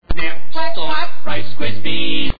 Commercial Sound Effects
The sound bytes heard on this page have quirks and are low quality.